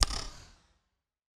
snap.wav